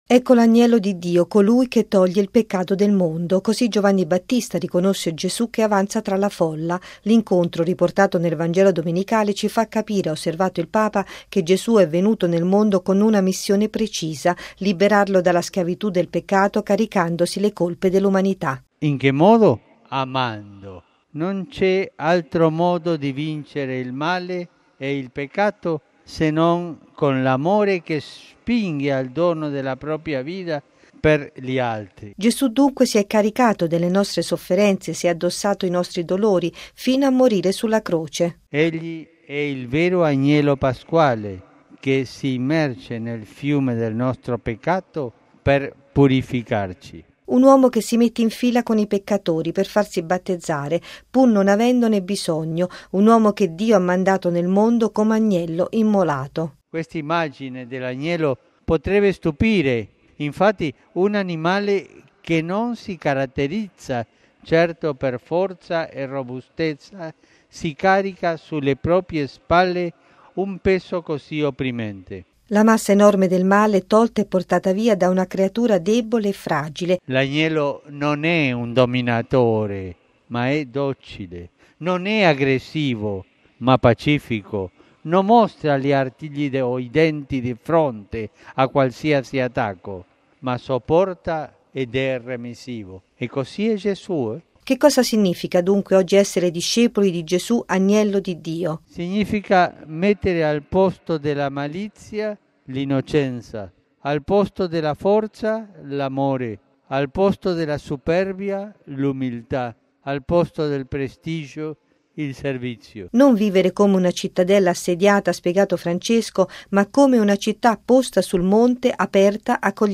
◊   “Seguire Gesù ci rende più liberi e gioiosi” Così il Papa stamane all’Angelus in piazza San Pietro, gremita di fedeli di diverse comunità etniche, nell’odierna Giornata mondiale del migrante e del rifugiato, sul tema “Verso un mondo migliore”.
Infine una speciale Ave Maria è risuonata nell’intera piazza San Pietro: